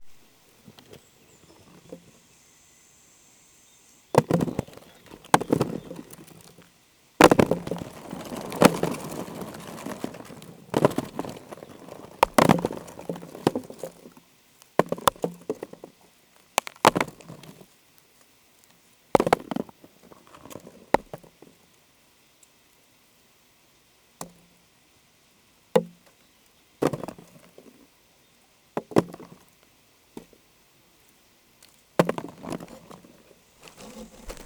Devinette sonore
quizz-cueillette-groseilles.wav